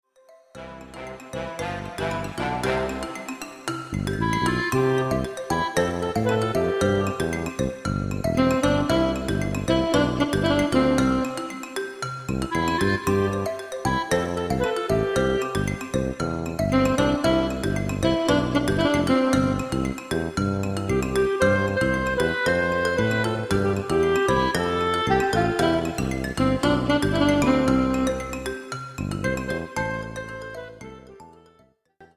Fatou_yo_extrait_accompagnement_avec_doublage_melodie_.mp3